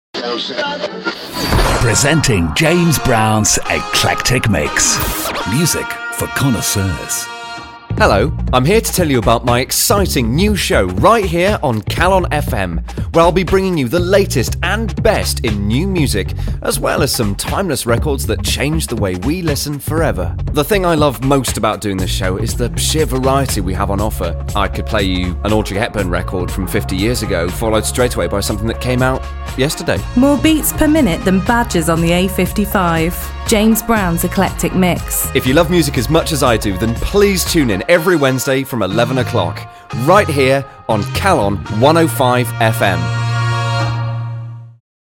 A brief trail for my new show on CalonFM, played out regularly around normal programming on the station throughout the day.